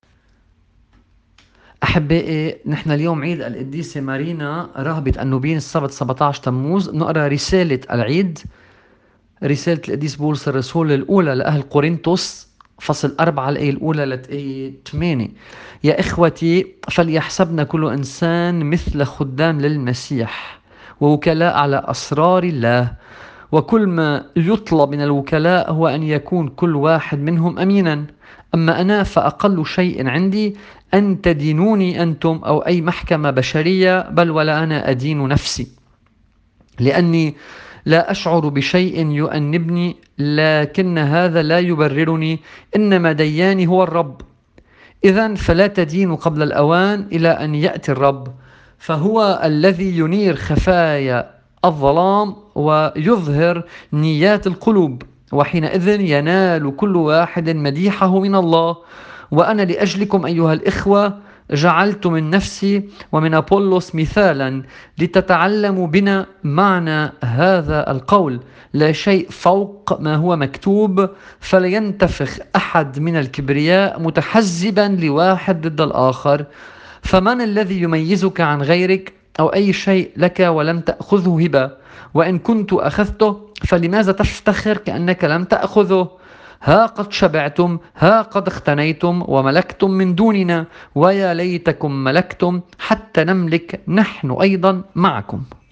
الطقس الماروني